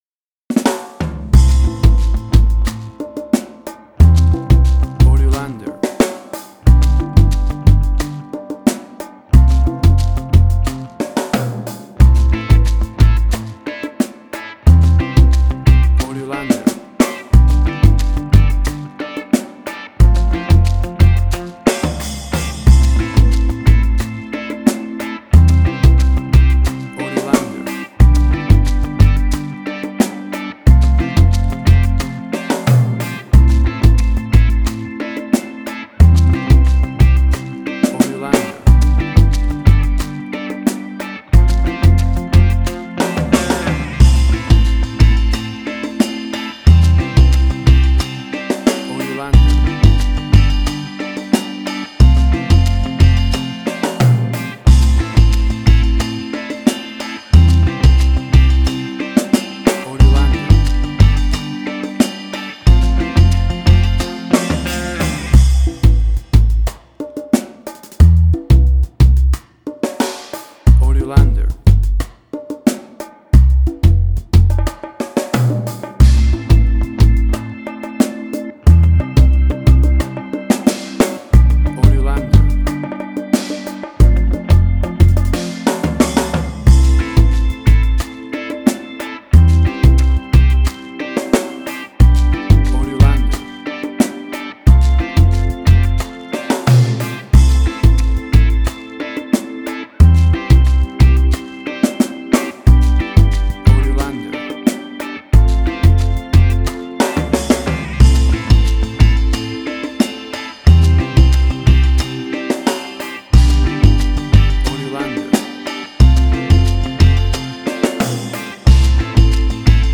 Reggae caribbean Dub Roots
Tempo (BPM): 90